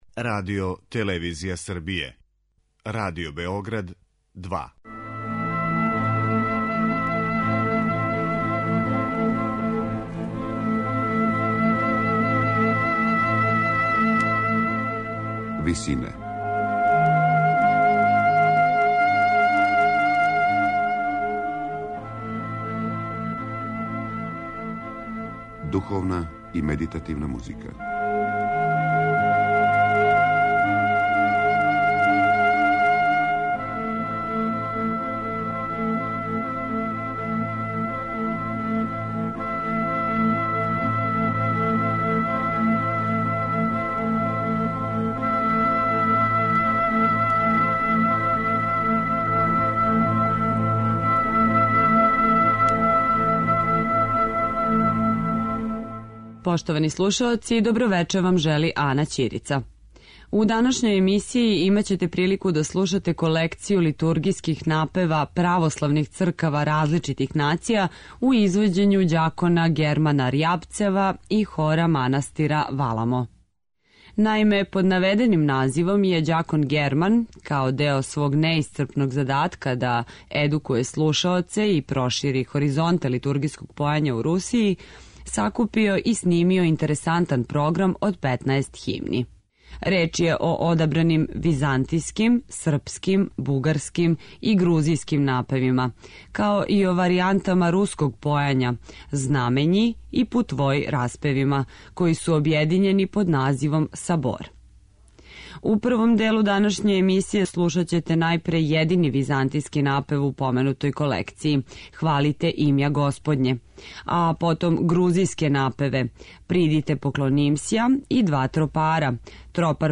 Из Колекције литургијских напева православних цркава различитих нација
Реч је о одабраним византијским, српским, бугарским и грузијским напевима
На крају програма, у ВИСИНАМА представљамо медитативне и духовне композиције аутора свих конфесија и епоха.